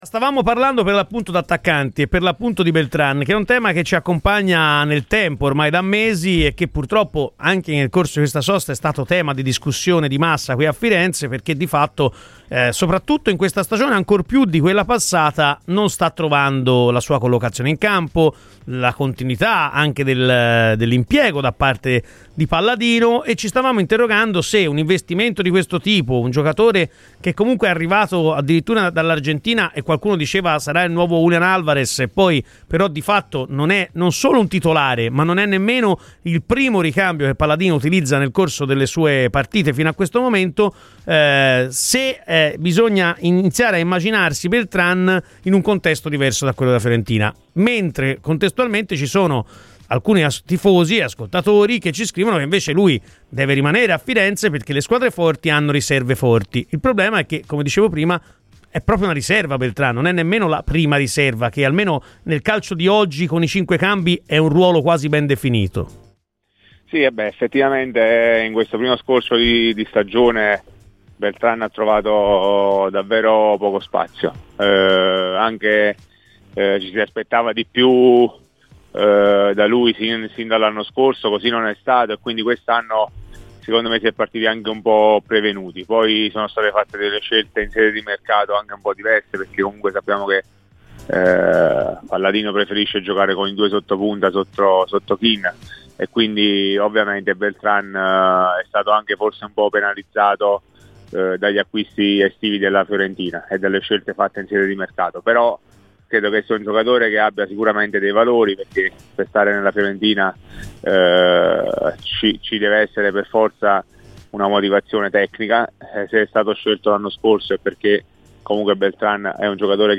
intervenuto ai microfoni di Radio FirenzeViola durante la trasmissione "Chi Si Compra